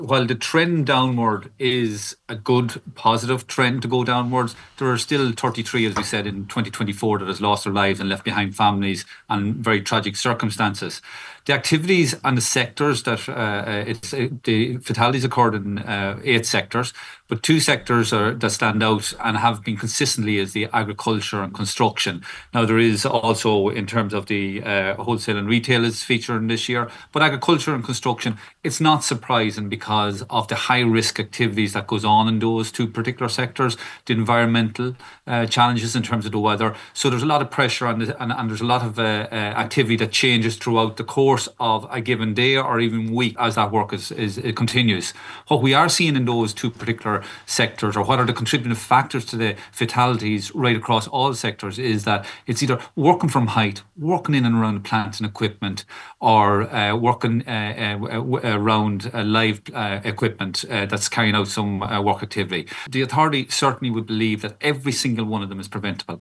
He says the tragedy is that all of these deaths are preventable……….